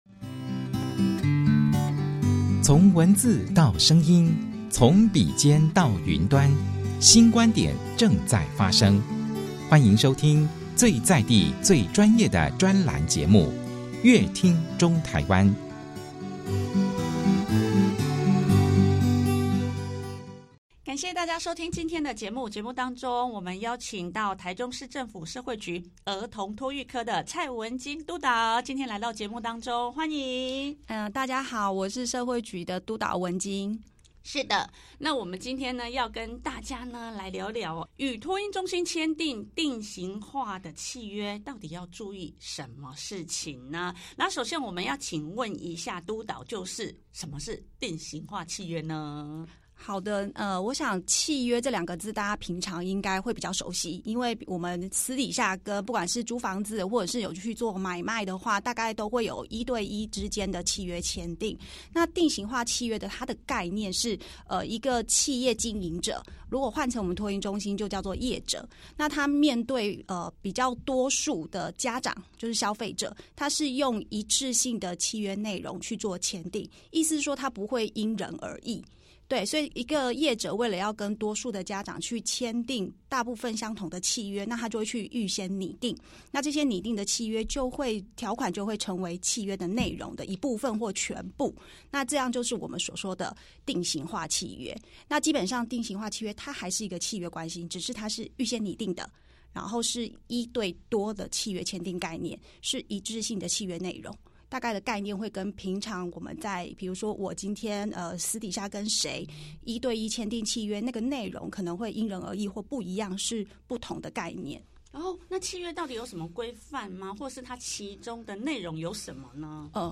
想知道更多精彩的專訪內容，歡迎大家鎖定本集節目收聽。